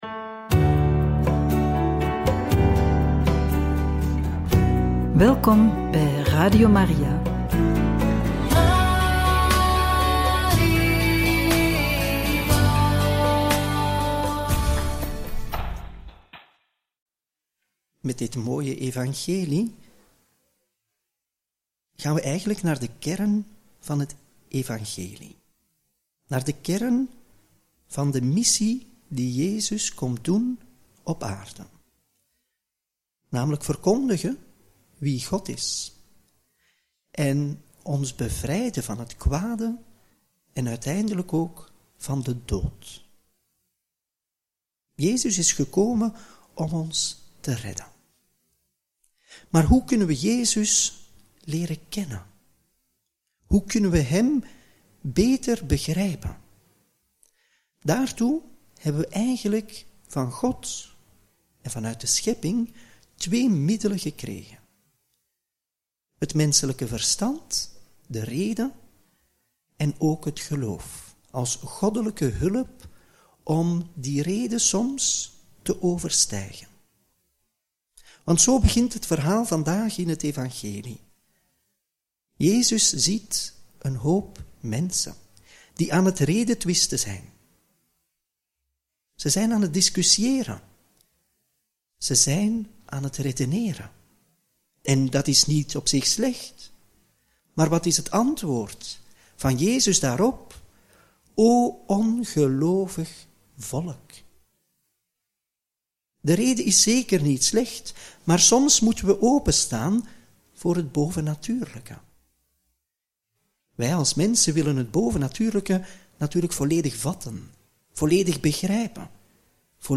Homilie bij het Evangelie van maandag 24 februari 2025 – Mc 9, 14-29